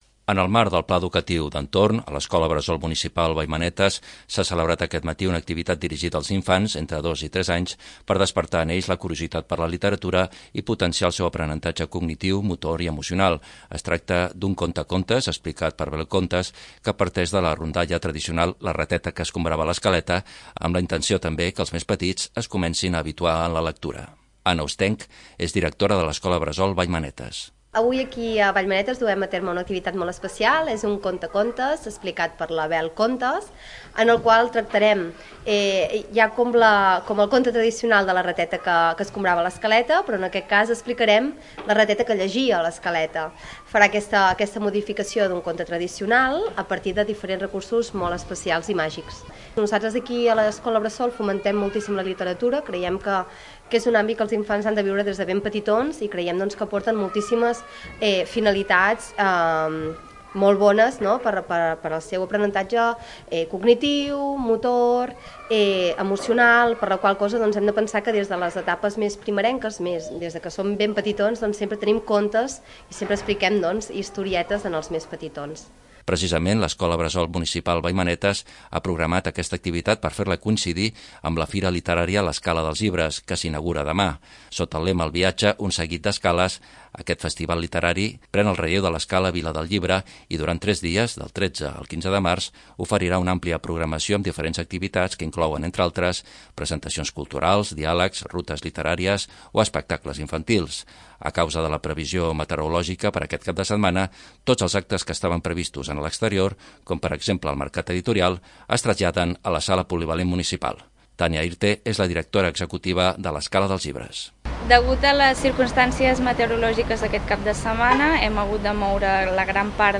Martí Guinart és regidor de Cultura i Patrimoni.